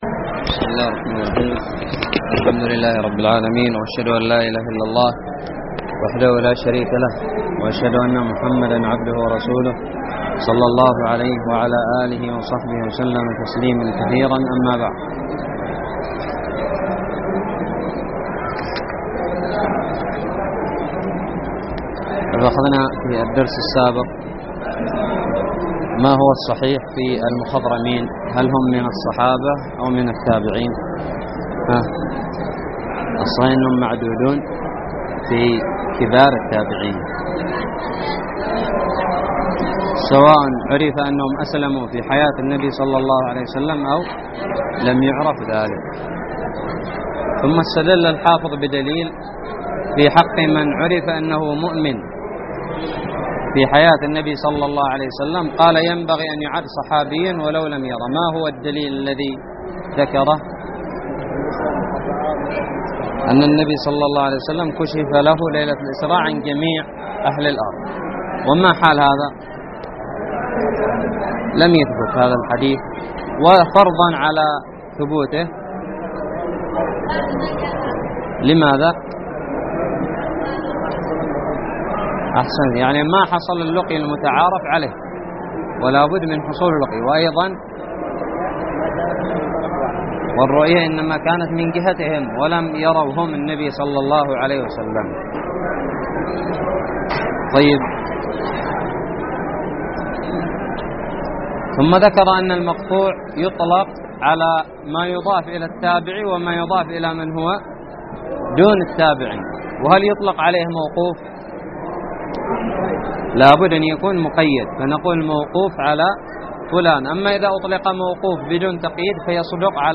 الدرس التاسع والثلاثون من شرح كتاب نزهة النظر
ألقيت بدار الحديث السلفية للعلوم الشرعية بالضالع